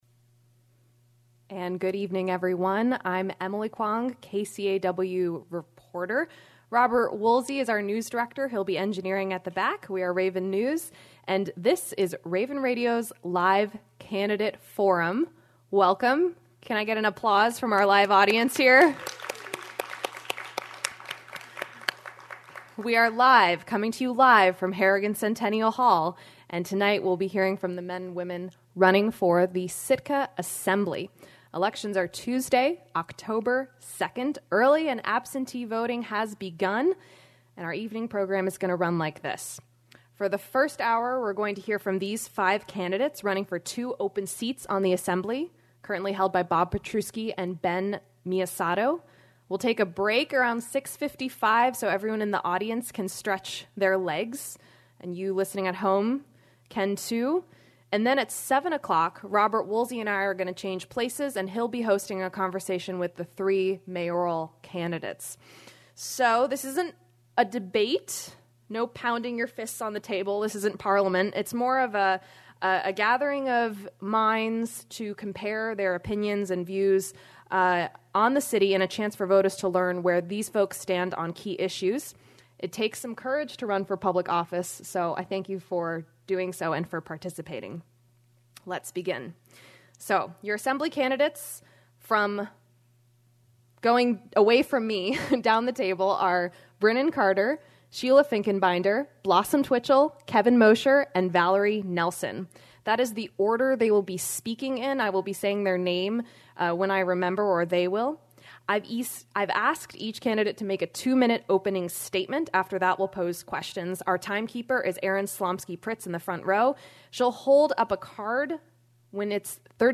Candidates for Sitka's municipal election met during a two-hour forum on Monday night (09-17-18) at Harrigan Centennial Hall to answer citizens' questions.